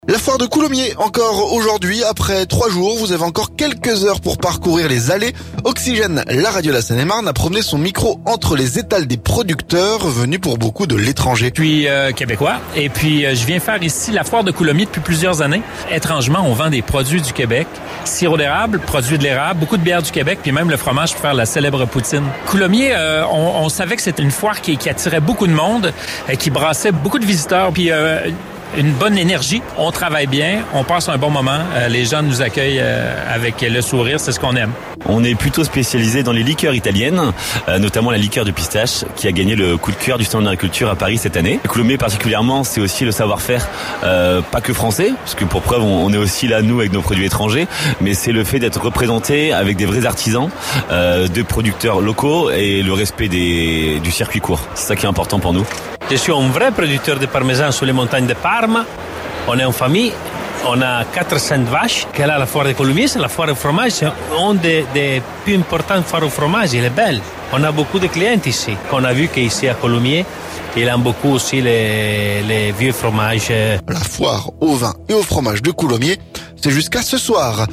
FOIRE DE COULOMMIERS - Fromage, sirop d'érable, liqueurs... Notre reportage au coeur des exposants
Oxygène, la radio de la Seine-et-Marne a promené son micro entre les étales des producteurs...